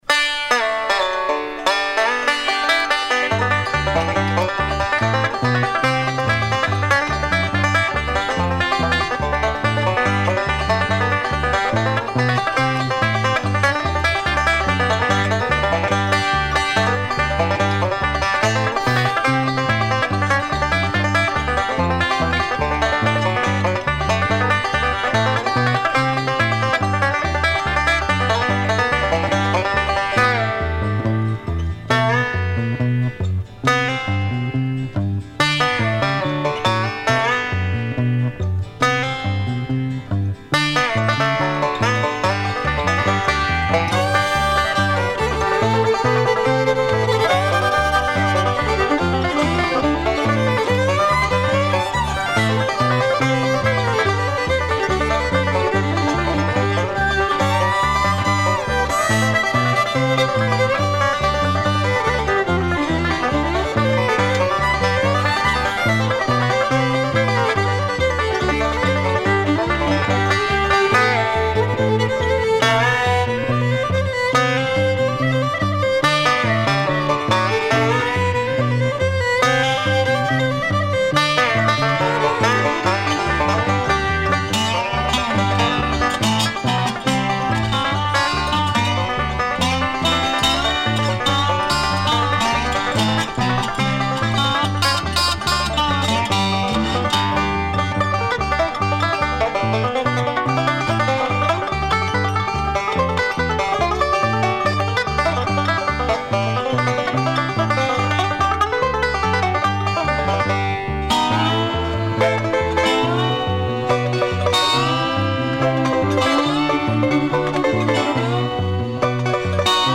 Back Porch Bluegrass Show - 18 September 2018